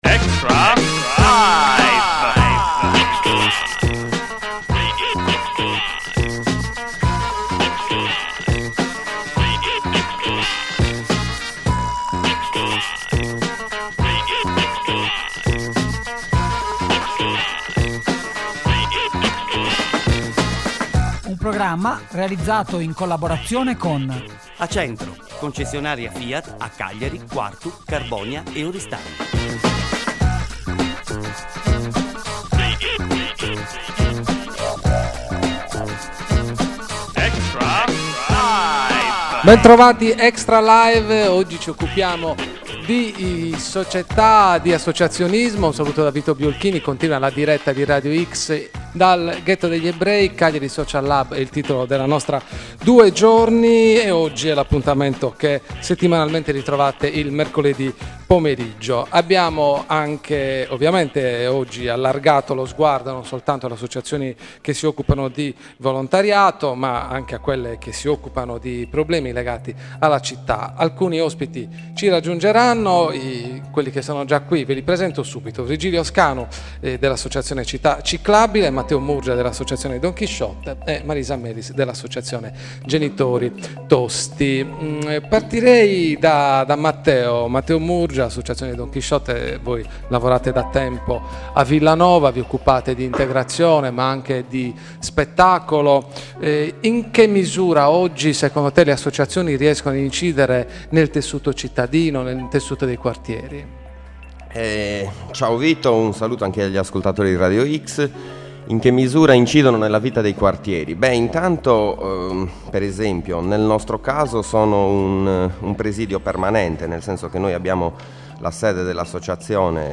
IL PODCAST DEL PANEL DEDICATO ALLA SOCIETA’ ALLE ASSOCIAZIONI E AI QUARTIERI